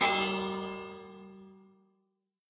Minecraft Version Minecraft Version 1.21.5 Latest Release | Latest Snapshot 1.21.5 / assets / minecraft / sounds / block / bell / bell_use01.ogg Compare With Compare With Latest Release | Latest Snapshot
bell_use01.ogg